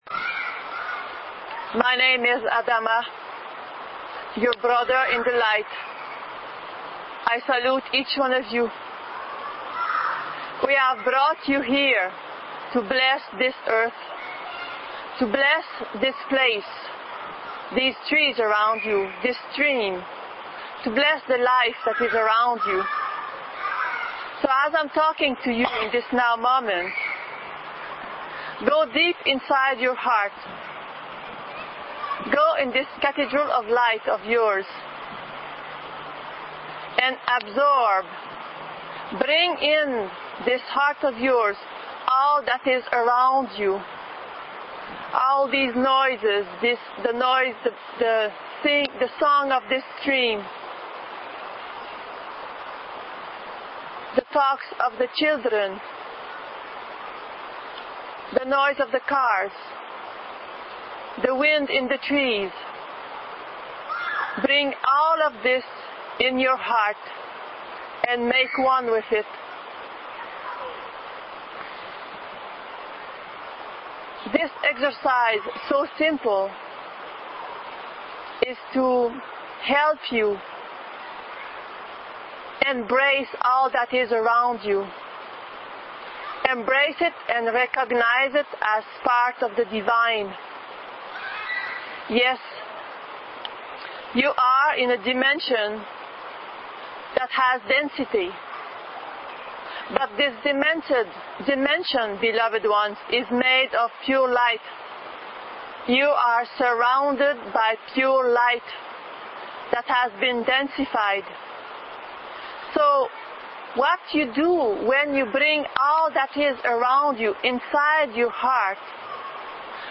Adama’s message was given during an Initiatic Journey in Mount Shasta, August 2012.